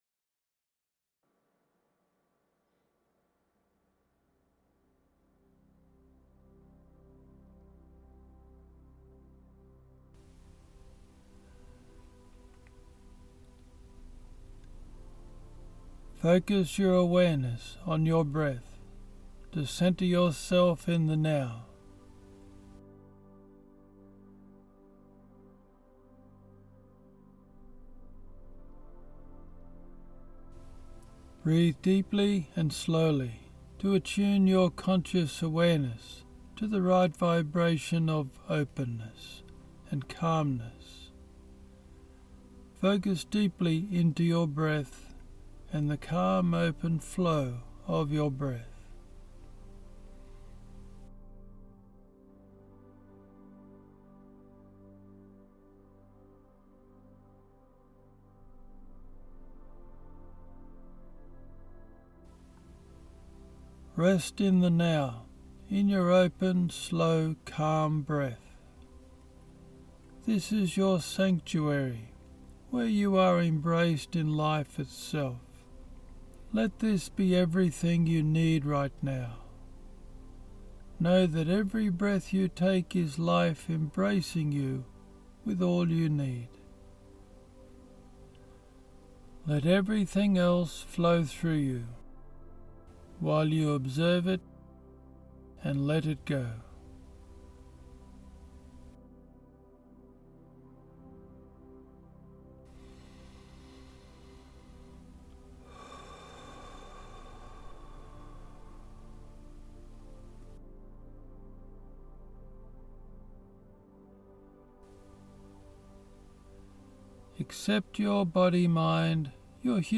This guided meditation is a beginner's level meditation, but is good to use by anyone as way to center and de-stress. This meditation focuses on being the compassionate, non-judging, non-reacting observer to your mind and body.